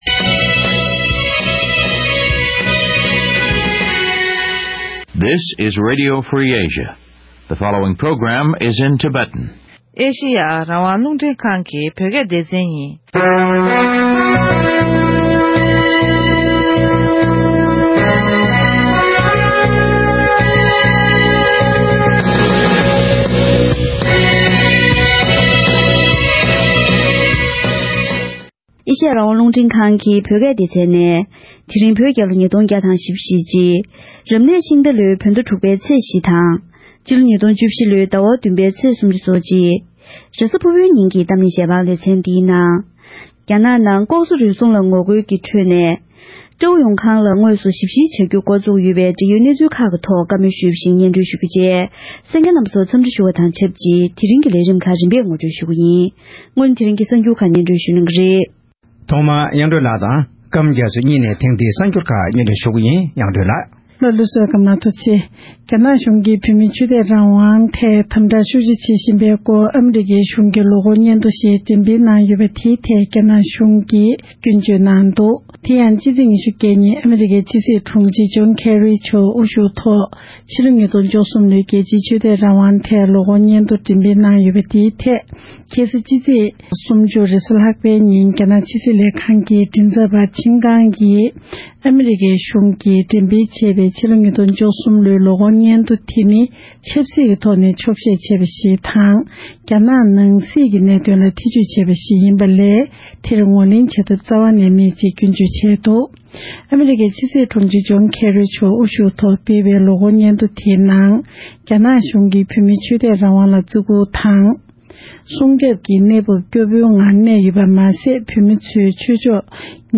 ༄༅། །དེ་རིང་གི་གཏམ་གླེང་ཞལ་པར་ལེ་ཚན་འདིའི་ནང་།